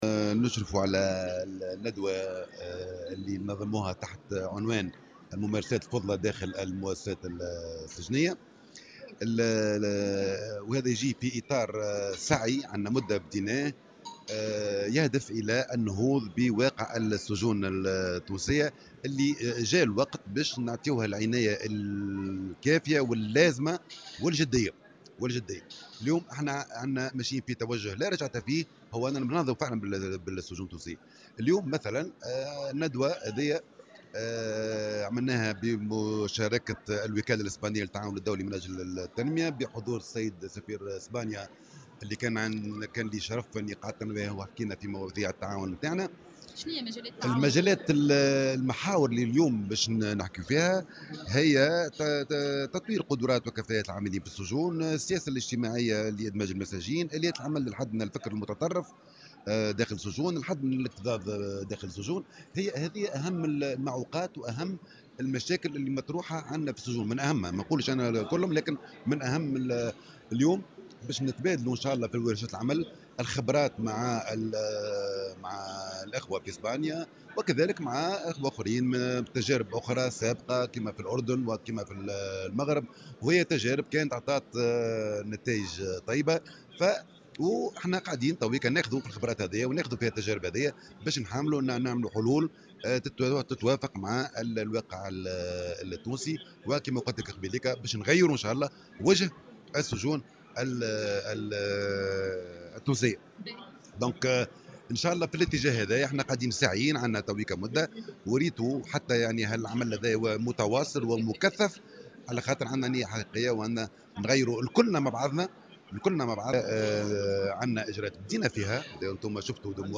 أكد وزير العدل عمر منصور خلال اشرافه اليوم الأربعاء 16 مارس 2016 على ندوة بعنوان الممارسات الفضلى داخل المؤسسات السجنية هذه الندوة تندرج في إطار السعي إلى النهوض بواقع السجون في تونس والتي حان الوقت لإيلائها العناية والاهتمام اللازمين بصفة جدية وفق قوله.